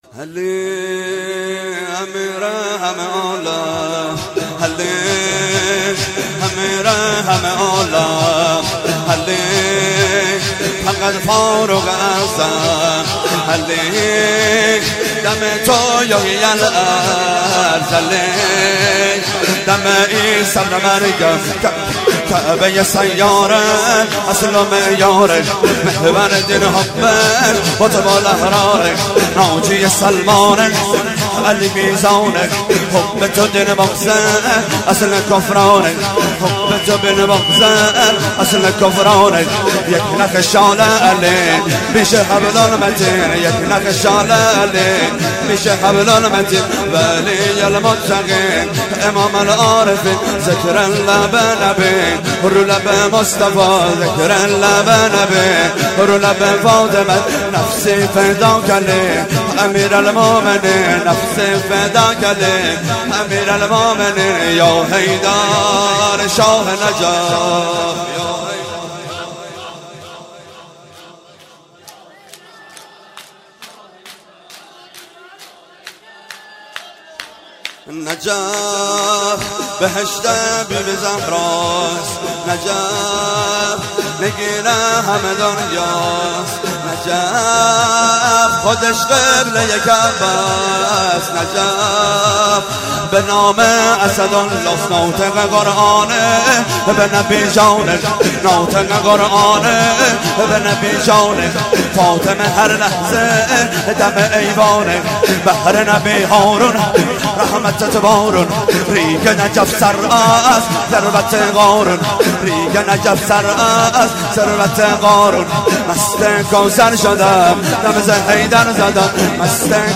شور - علی امیر همه عالم
شب دوم ویژه برنامه فاطمیه دوم ۱۴۳۹